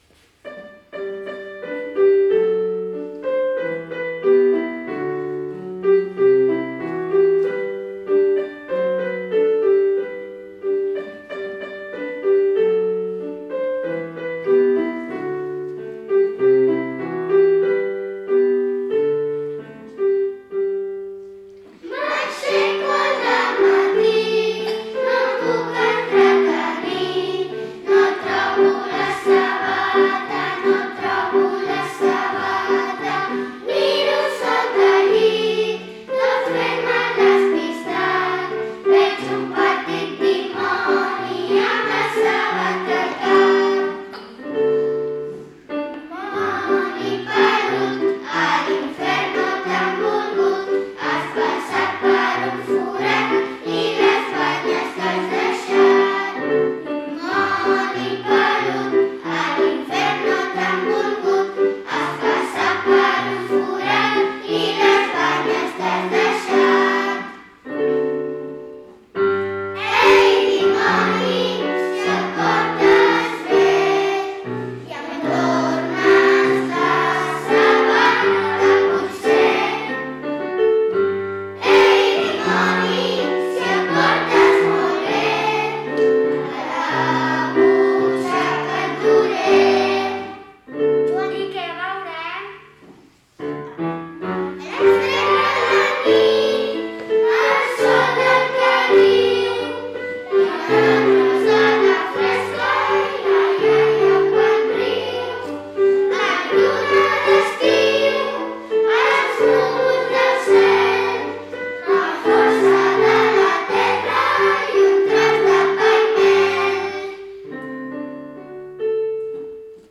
Enguany per celebrar aquestes festes a distància, hem gravat uns vídeos per a cada classe amb les seves cançons.